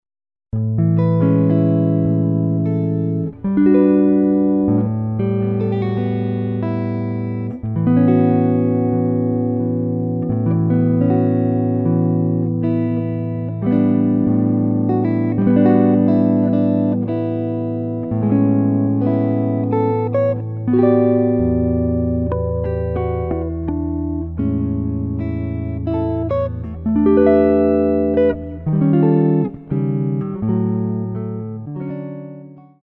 solo guitar arrangements